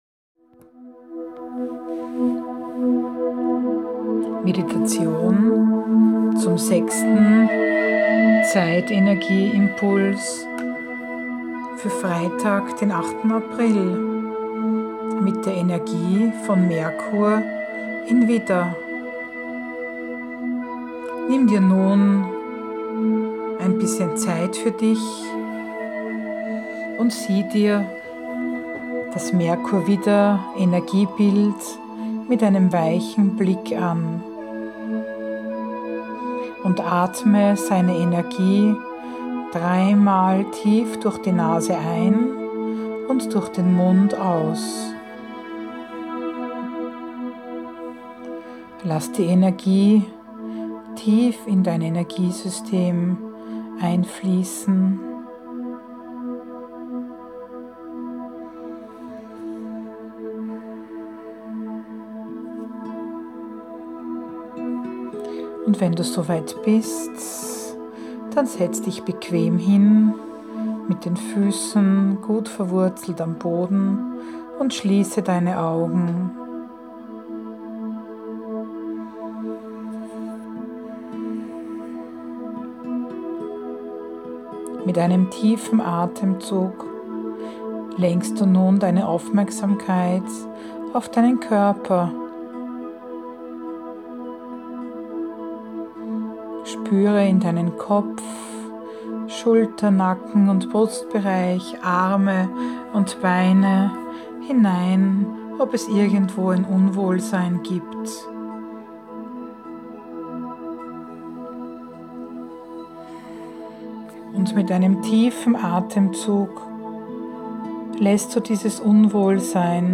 Heilmeditation_Gedankenflut.mp3